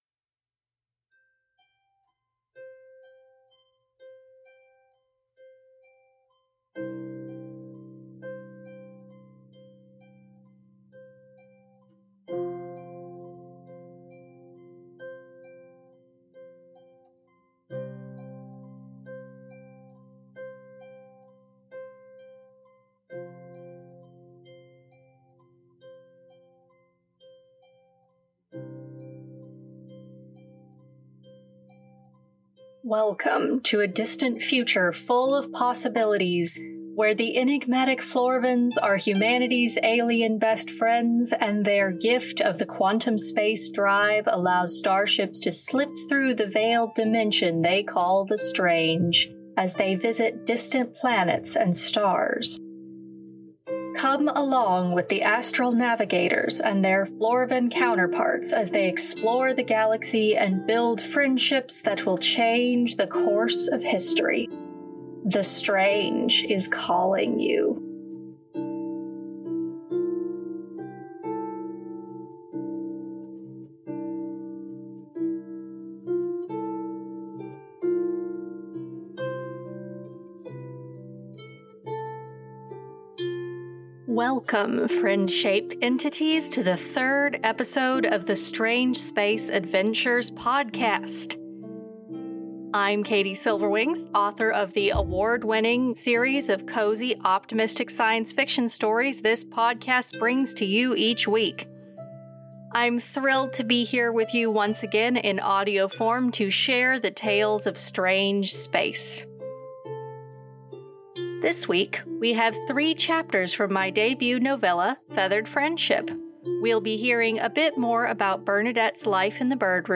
reads chapters three through five